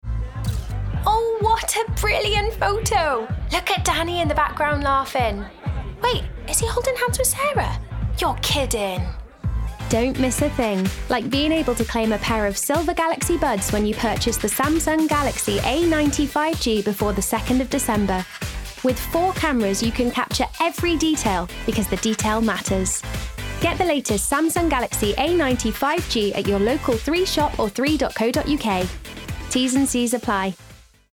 20/30's Welsh/Neutral,
Bright/Youthful/Fun